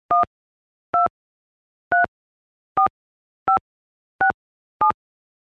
Звуки набора телефона
В коллекции представлены как современные тональные сигналы мобильных устройств, так и характерный щелкающий звук старого дискового телефона.